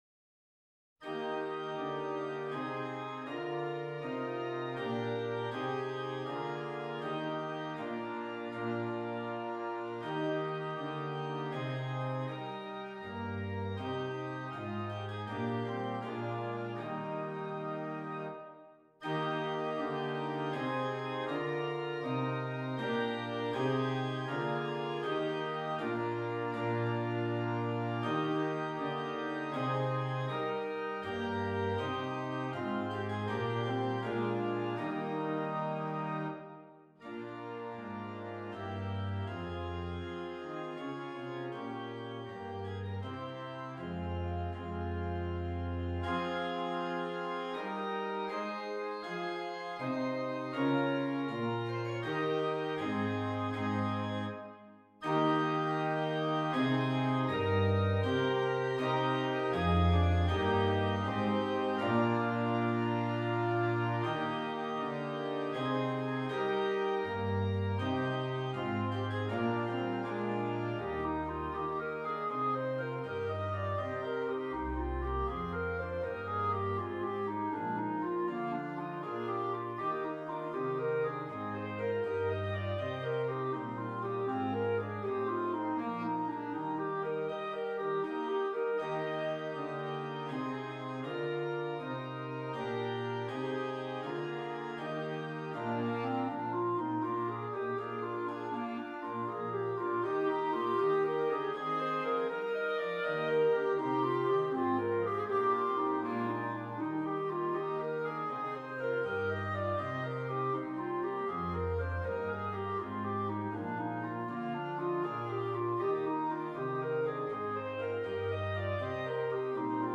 2 Clarinets and Keyboard